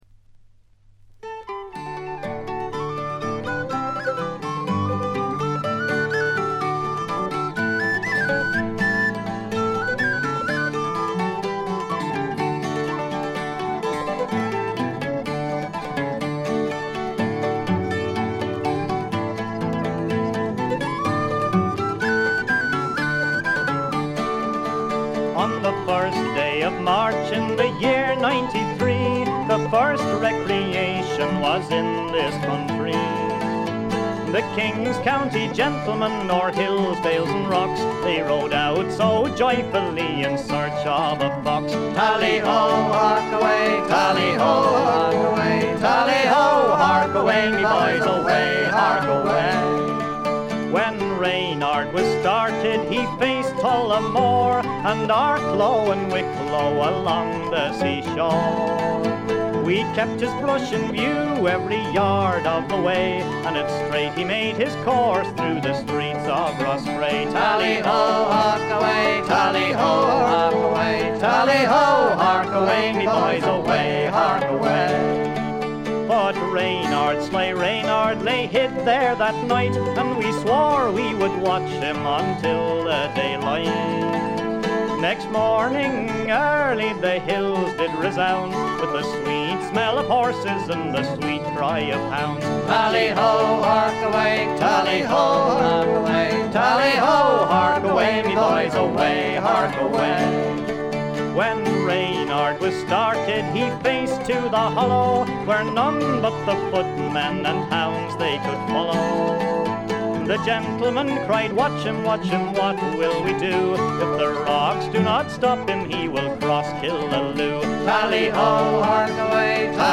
試聴曲は現品からの取り込み音源です。
Vocals, Bouzouki, Tin Whistle
Vocals, Guitar, Banjo, Concertina
Vocals, Mandolin, Harmonica, Guitar